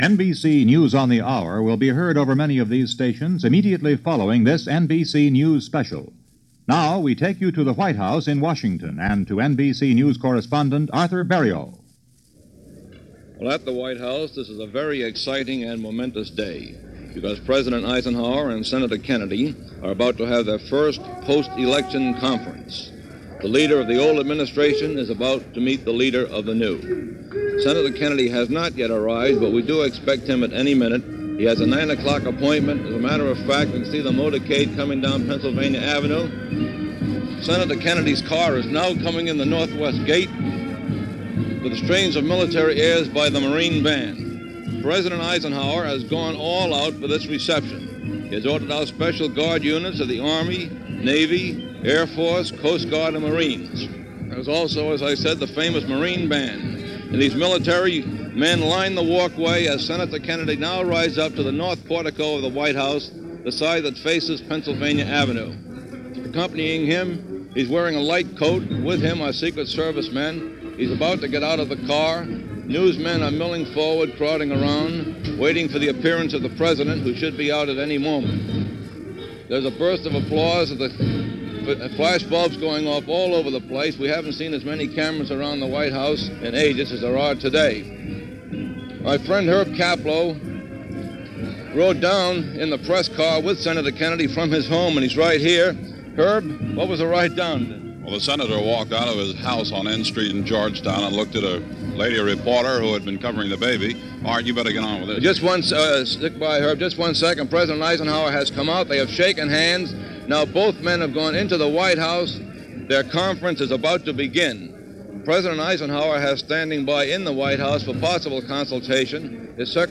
December 6, 1960 – Special Report – JFK Comes To The White House – News on The Hour – NBC Radio